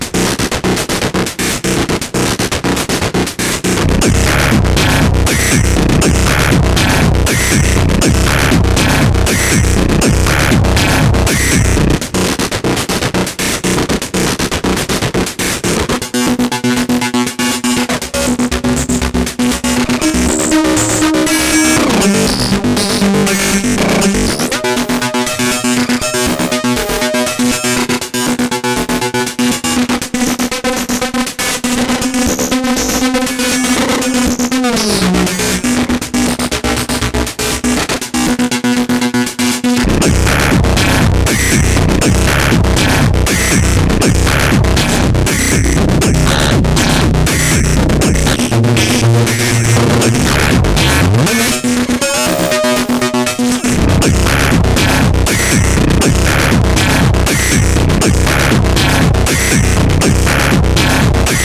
Latest sounds from my Hydrasynth, using the mutants to make some harsh sounds or add a little bit of grit and noise to some pad sounds.